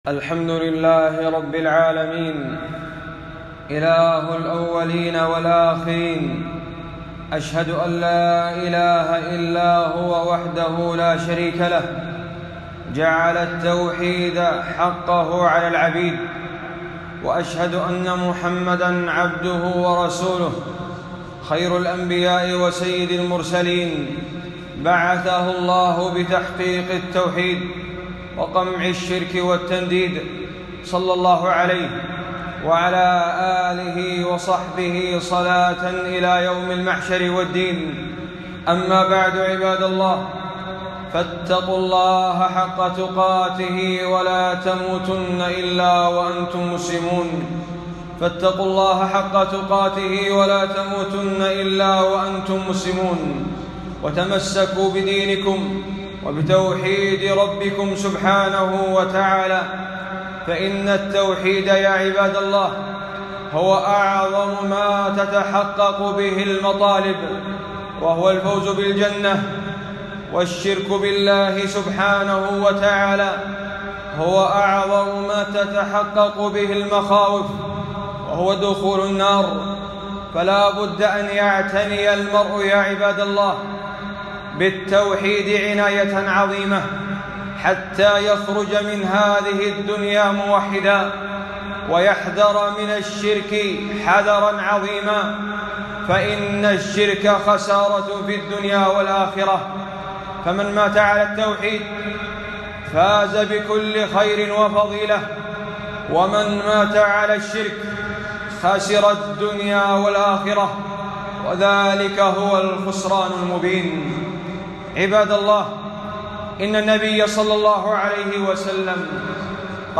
خطبة - إن الرقى والتمائم والتولة شرك